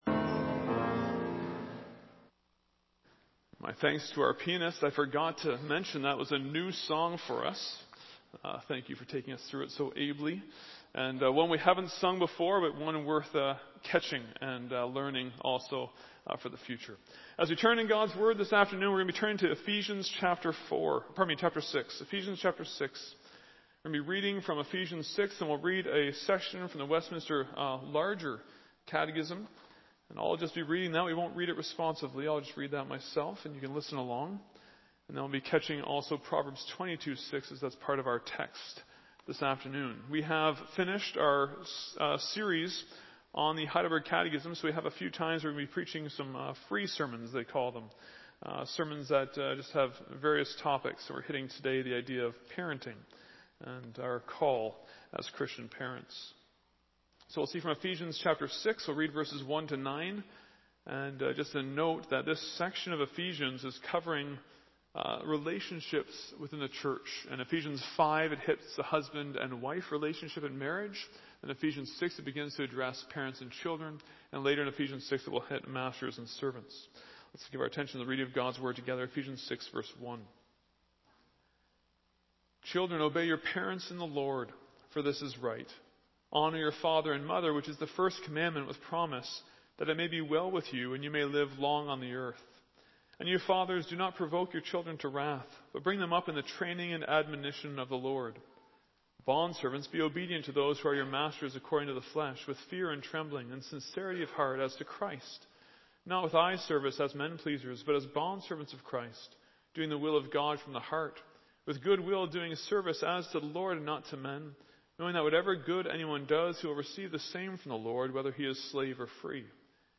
Non-Series Sermon